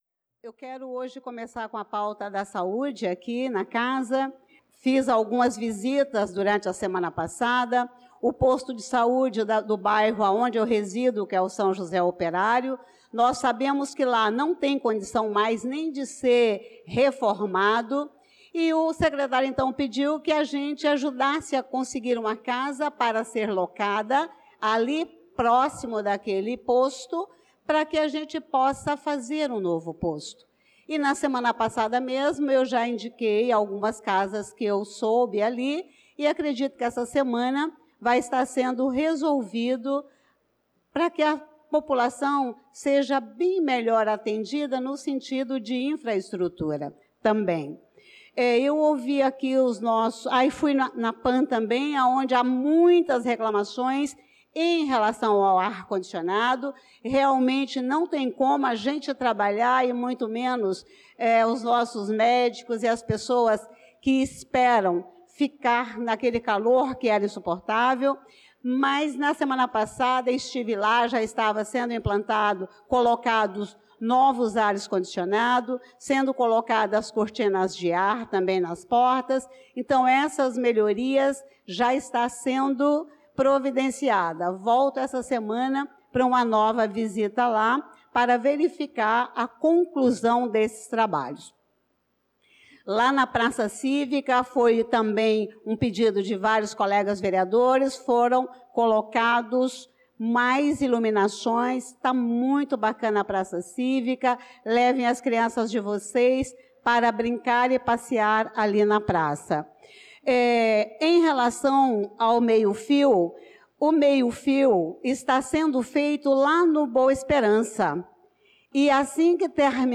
Pronunciamento da vereadora Elisa Gomes na Sessão Ordinária do dia 16/06/2025.